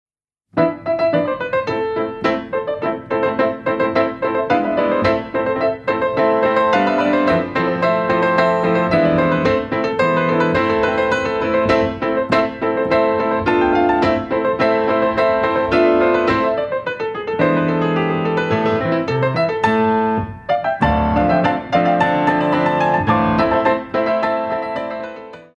Compositions for Ballet Class
Exercise face à la barre 1ere & 5ere